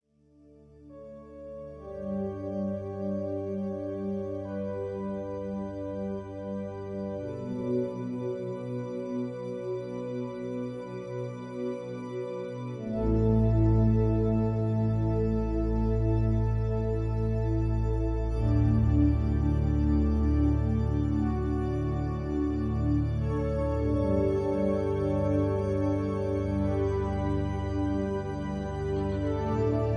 rock and roll, rock